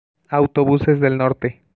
Summary Description Es-mx-Autobuses del Norte.wav English: Spanish pronunciation for "Autobuses del Norte" — Altiplano Mexican Spanish dialect. Español: Pronunciación de "Autobuses del Norte" en español central mexicano, por un hablante nativo.
[au.toˈβuses ðel ˈnoɾ.te]